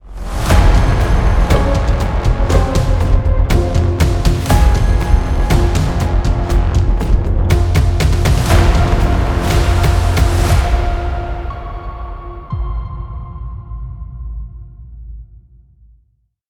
Genre: trailer, production.